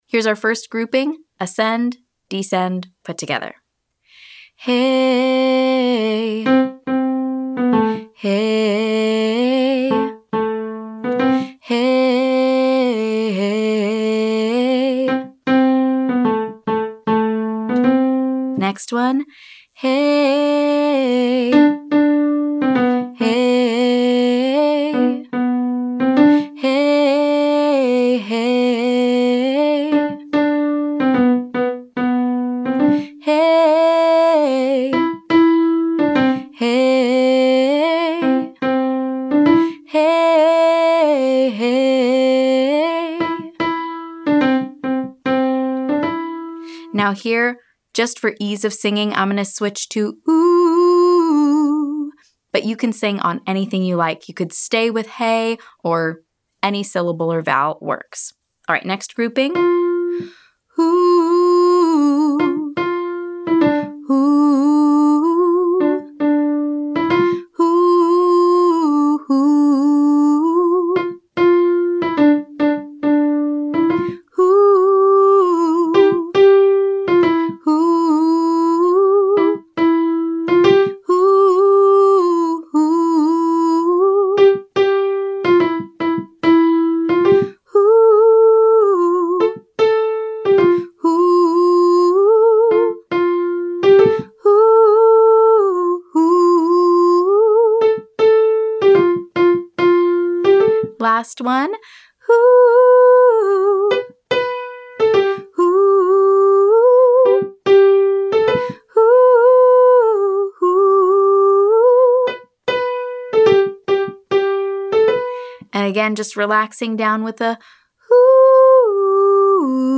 Let’s learn this, listen & repeat style.
[Exercise: 3-note riffs up the scale, starting with a pulse]